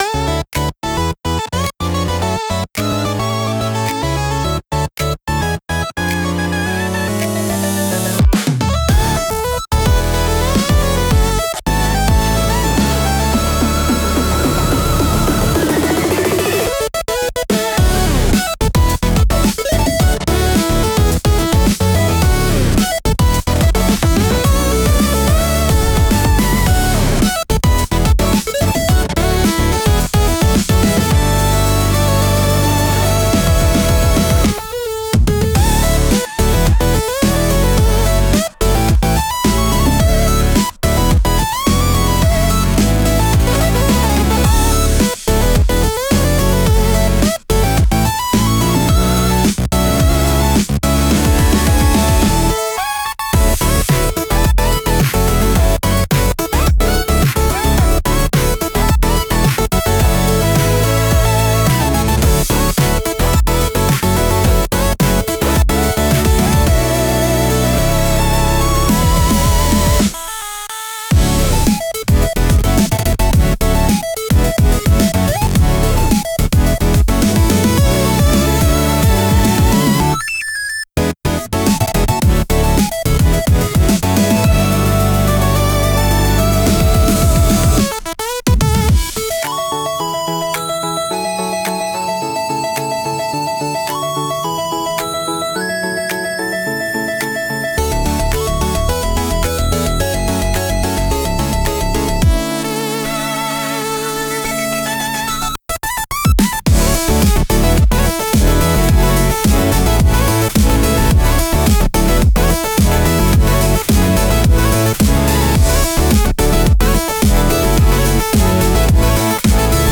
BGM / Instrumental